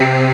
ORGAN-30.wav